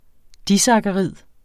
Udtale [ ˈdisɑgaˌʁiðˀ ]